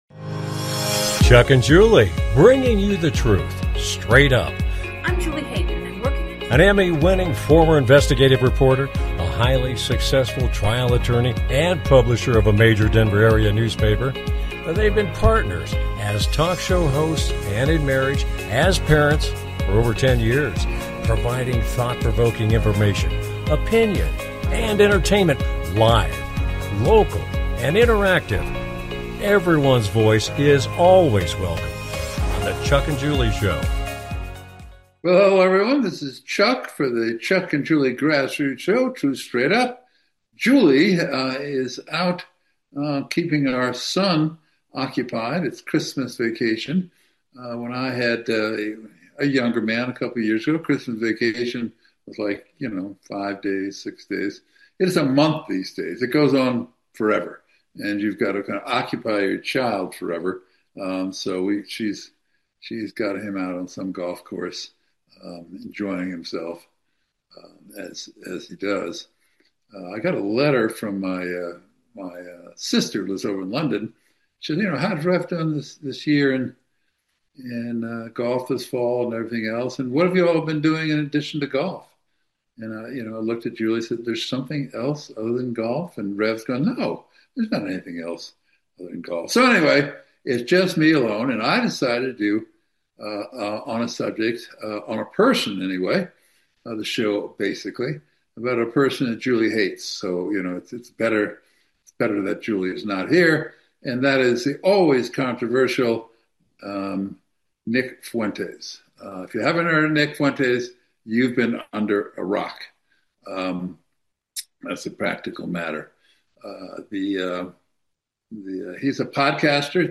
Talk Show Episode, Audio Podcast
Their program is a live Internet call-in talk show providing thought provoking information, conversation and entertainment.